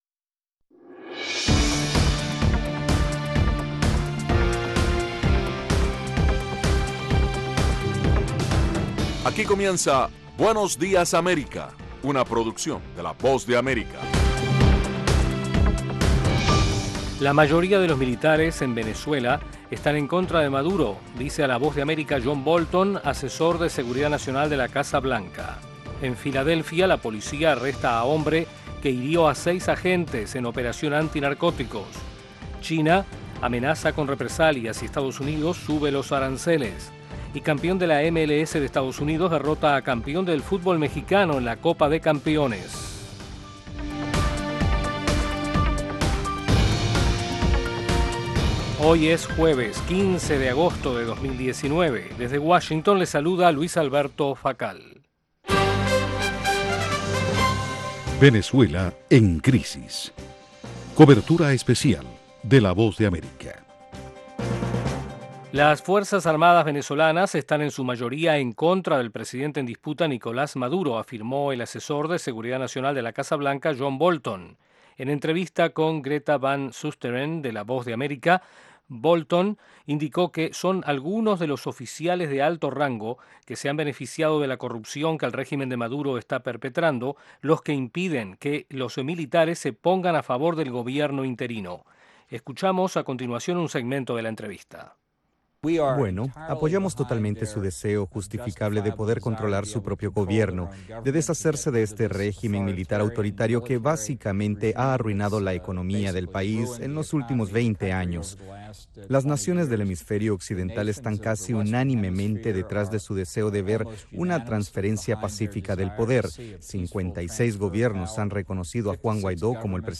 Información ágil y actualizada en las voces de los protagonistas con todo lo que sucede en el mundo, los deportes y el entretenimiento.